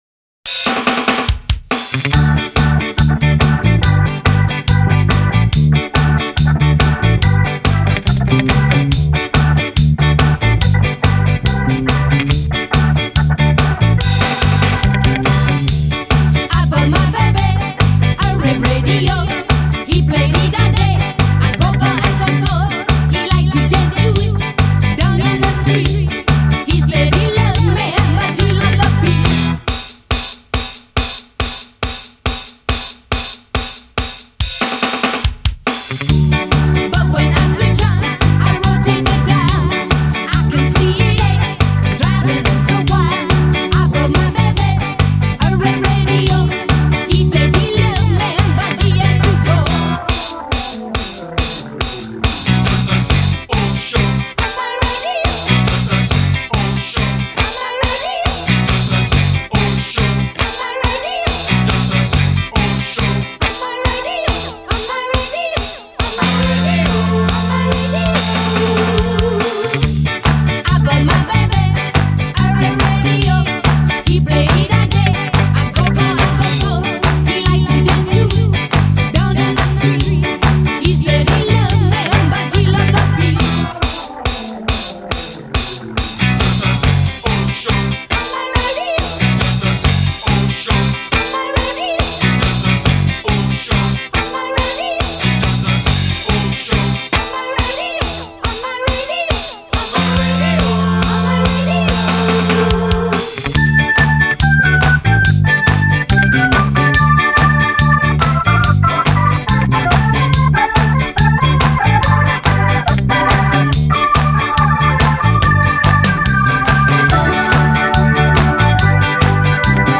Genre: Ska, Two-Tone, New Wave, Reggae